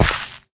gravel4.ogg